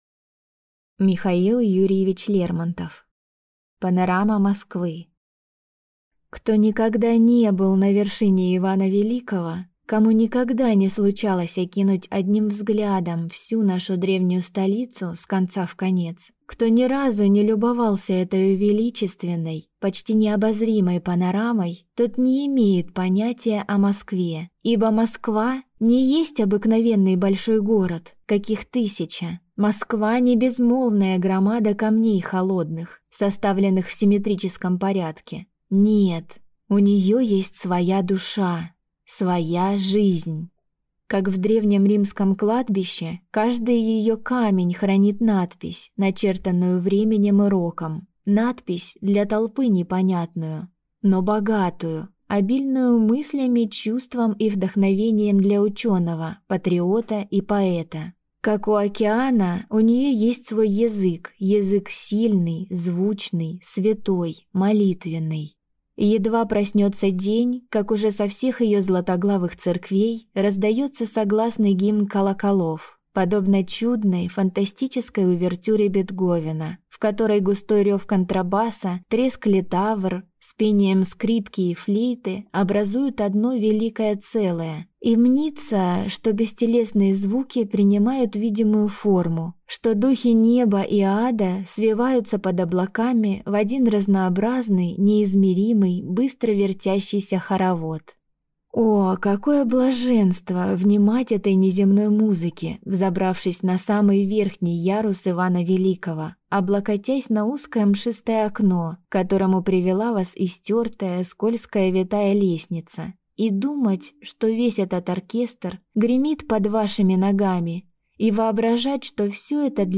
Аудиокнига Панорама Москвы | Библиотека аудиокниг